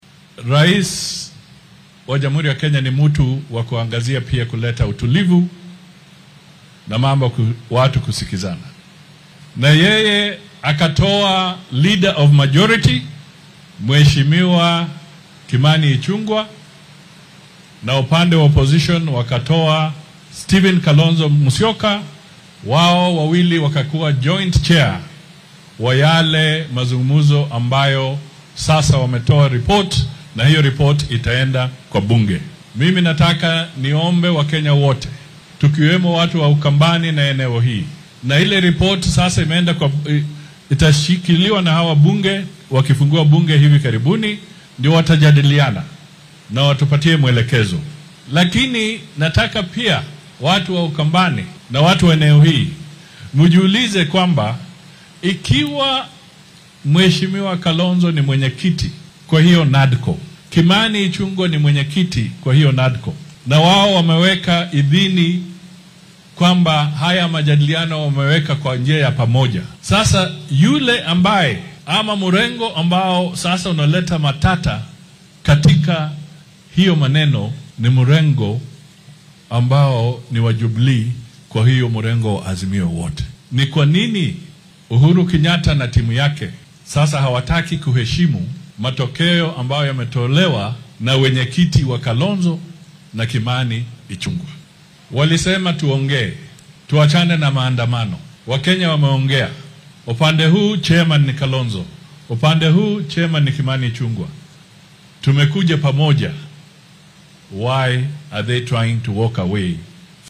Musalia Mudavadi ayaa xilli uu ku sugnaa ismaamulka Makueni hoosta ka xarriiqay in Jubilee ay ka tirsan tahay garabka mucaaradka ee Azimio oo ay wada hadallada ku matalayeen xubnaha uu hor kacayay hoggaamiyaha Wiper Kalonzo Musyoka.